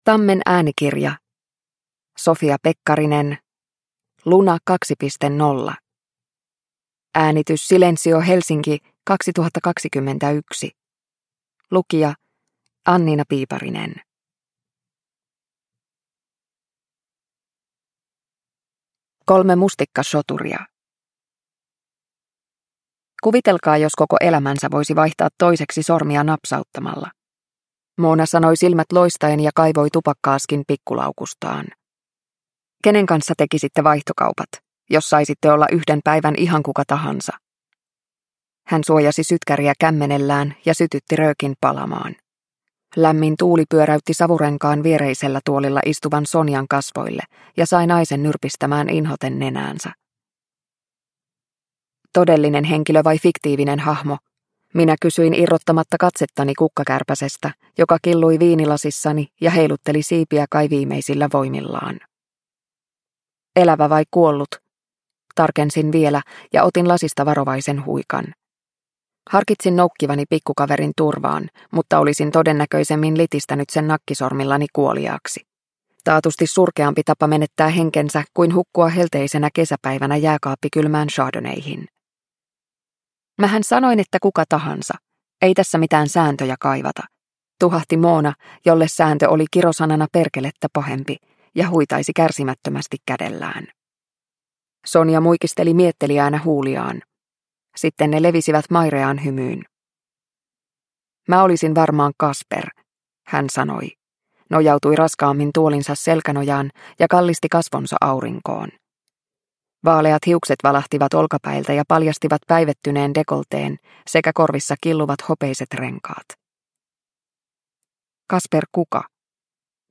Luna 2.0 – Ljudbok – Laddas ner